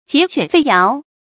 桀犬吠尧 jié quǎn fèi yáo
桀犬吠尧发音